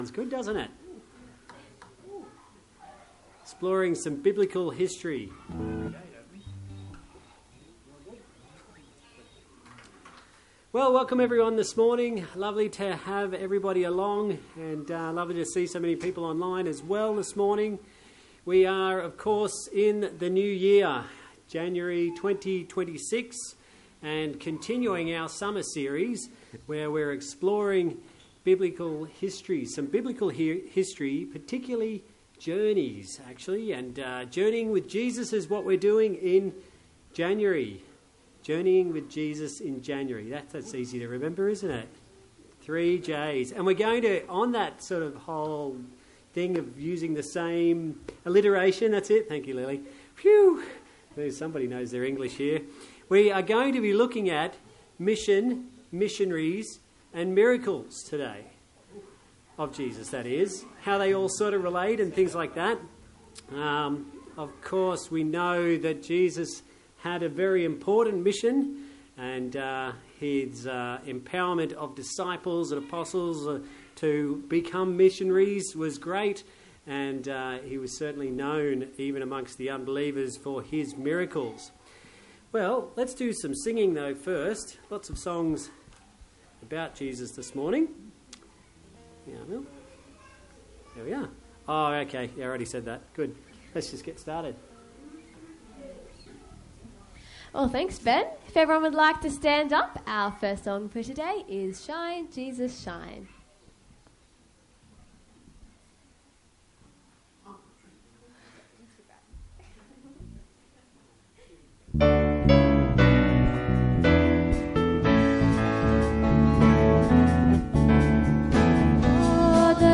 Service Type: Sunday Church